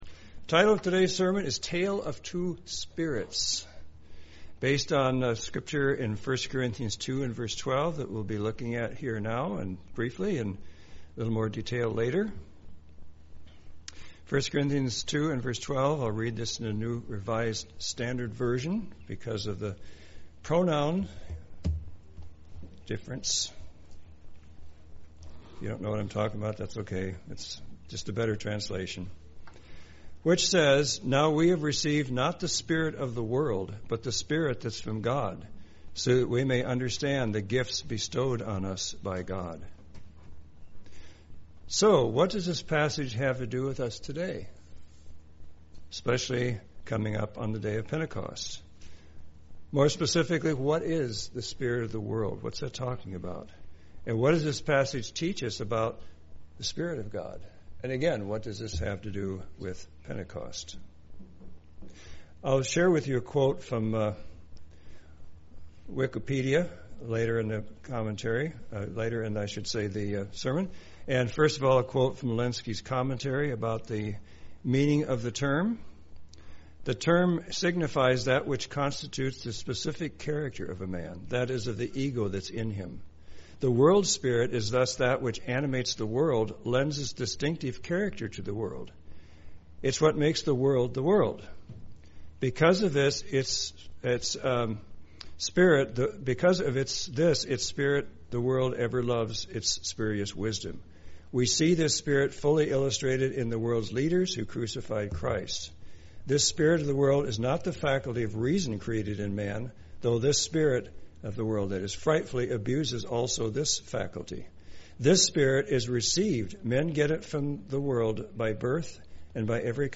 This sermon takes a closer look at the spirit of the world and the spirit of God and how it relates to Pentecost.